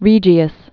(rējē-əs)